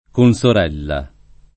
[ kon S or $ lla ]